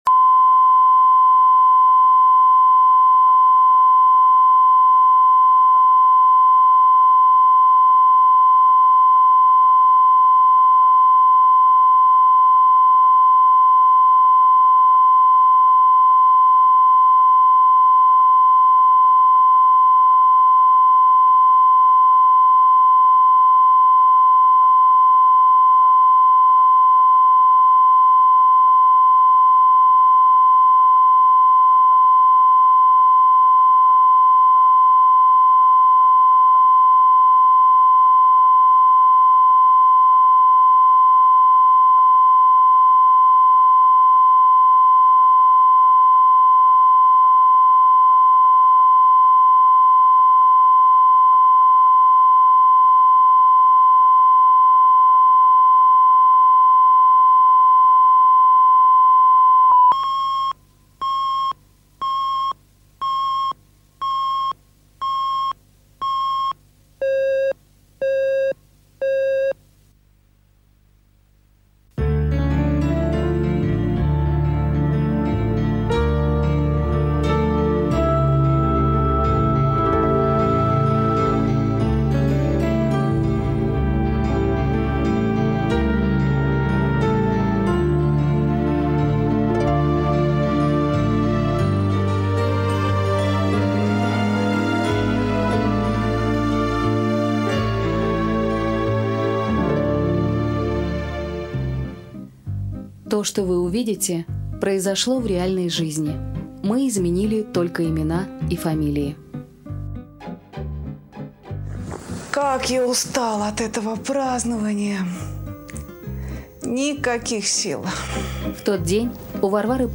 Аудиокнига Чужой ребенок | Библиотека аудиокниг